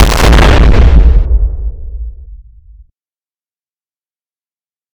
nuke_irontnt.ogg